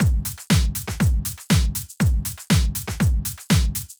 Drumloop 120bpm 07-A.wav